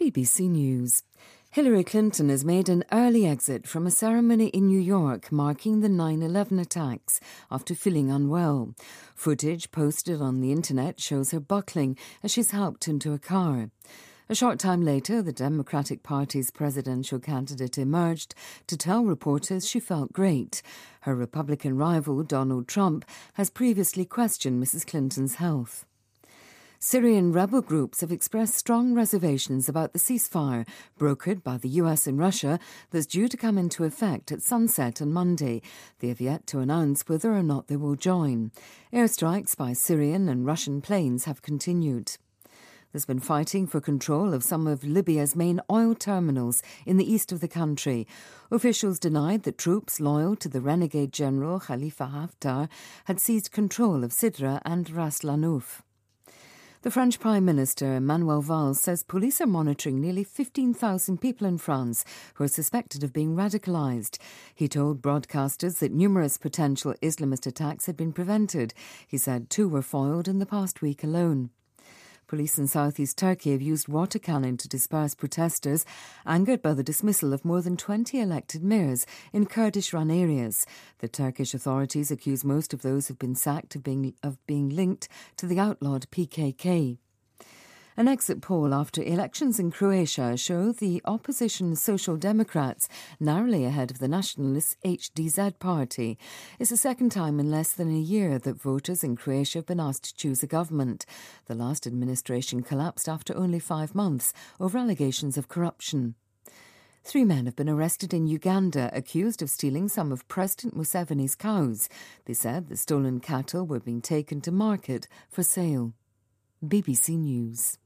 日期:2016-09-13来源:BBC新闻听力 编辑:给力英语BBC频道